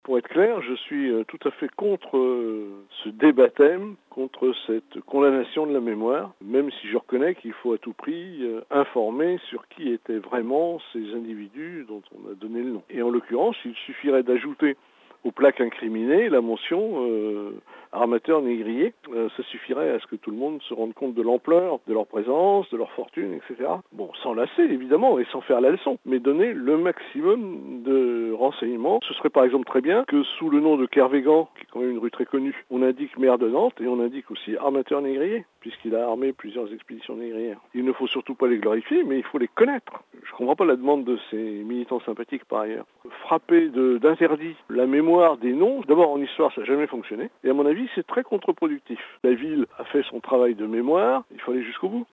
SUN + esclavagisme Frap Info Interview mémoire collective Société Société traite des noirs Esclavagisme: faut-il rebaptiser certaines rues de Nantes?